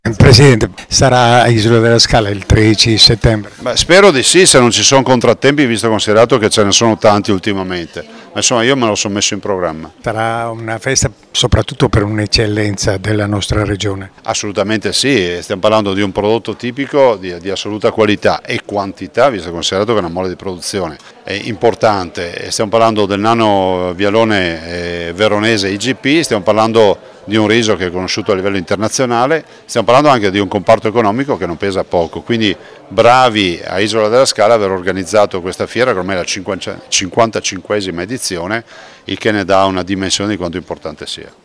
Le dichiarazioni raccolte dal nostro corrispondente
Luca Zaia, Governatore della Regione Veneto
Luca-Zaia-alla-presentazione-della-Fiera-del-Riso-di-Isola-della-Scala.wav